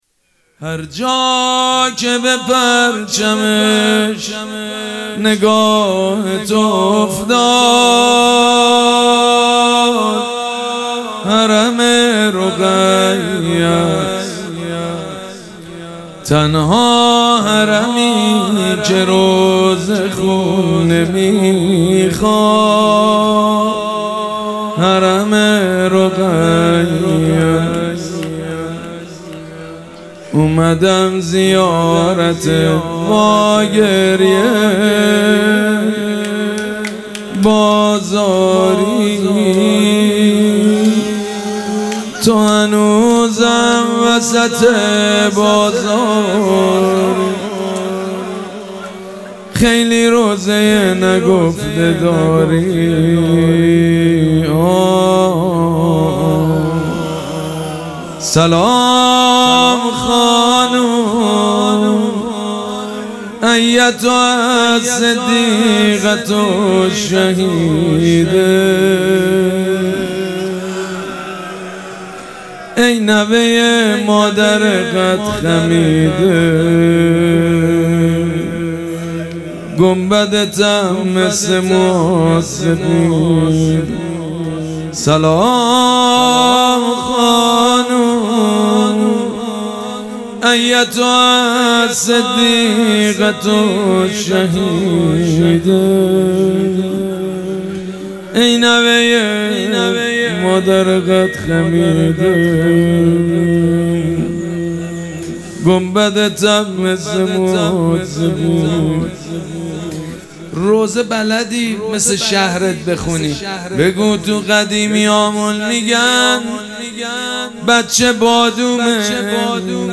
شب اول مراسم عزاداری اربعین حسینی ۱۴۴۷
زمزمه
مداح
حاج سید مجید بنی فاطمه